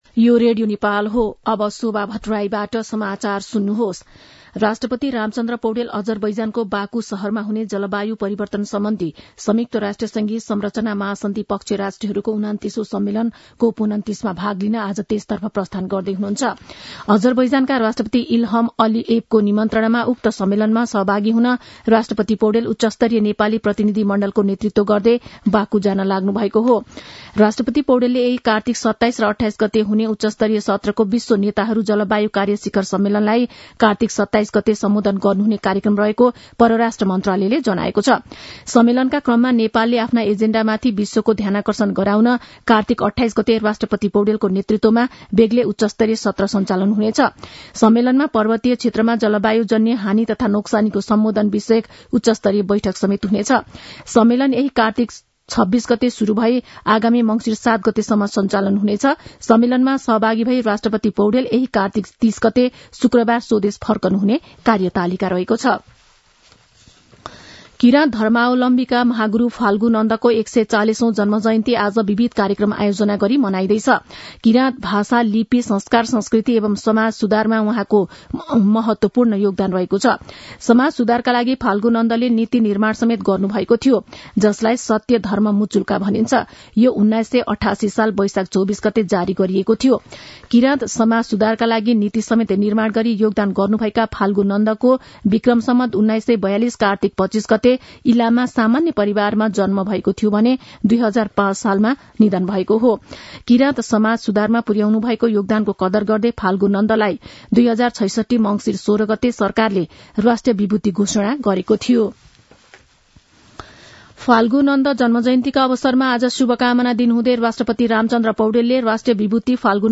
मध्यान्ह १२ बजेको नेपाली समाचार : २६ कार्तिक , २०८१